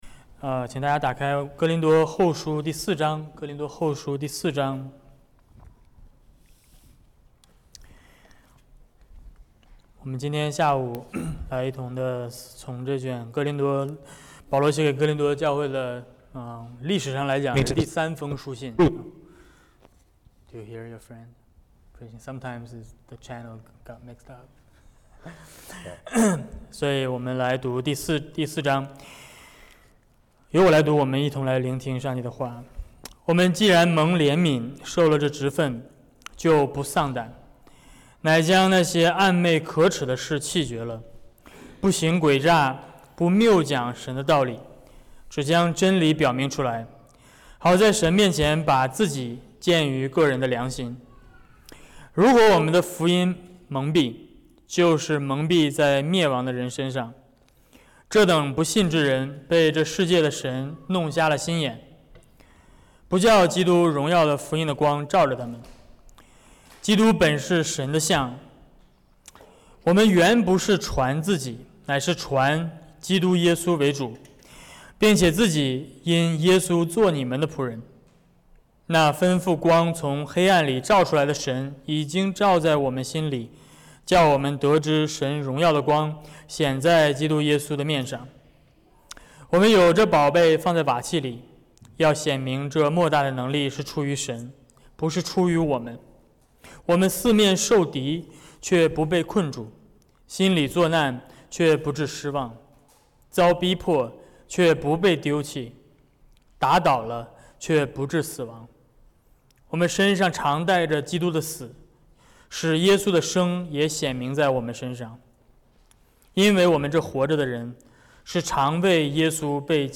外来牧师讲道｜基督徒的心理学(哥林多后书4:7-15)